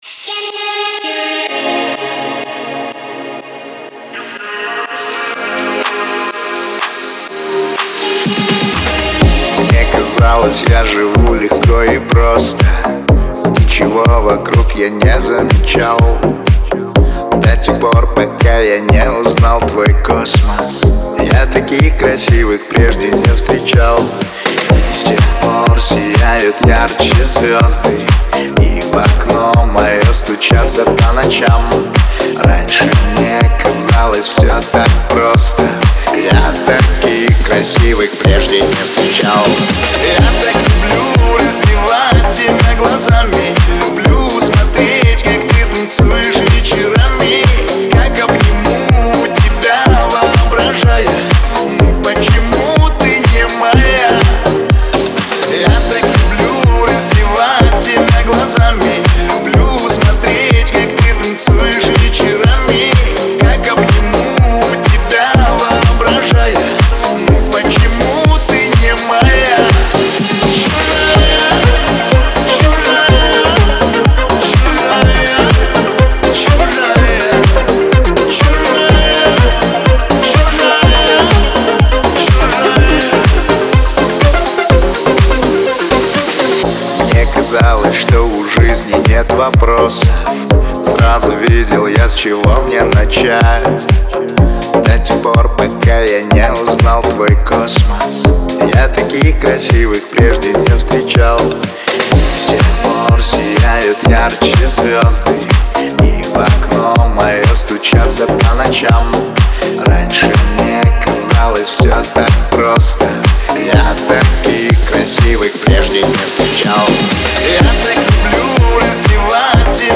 это яркий трек в жанре поп с элементами EDM